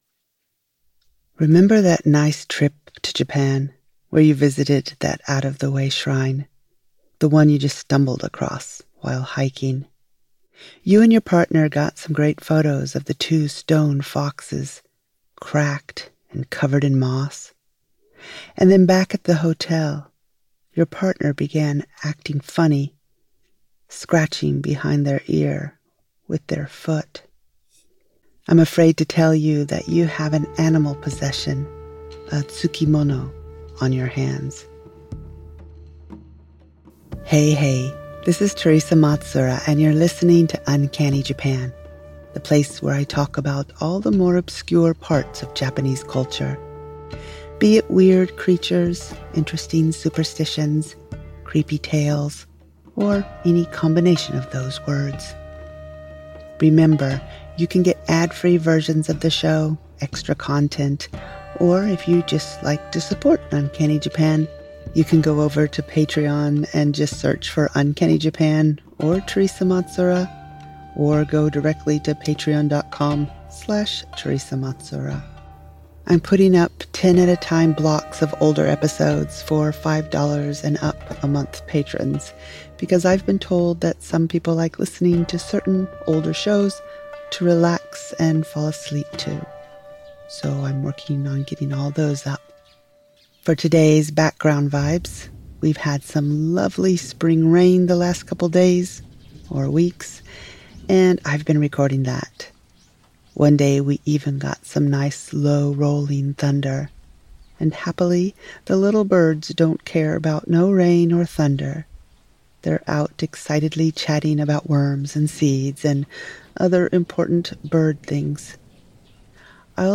As for today’s background vibes, we’ve had some lovely spring rain the last couple days, so I’ve been recording that. One day we even got some nice low rolling thunder. And happily, the little birds don’t care about no rain or thunder, they’re out excitedly chatting about worms and seeds and other important bird things.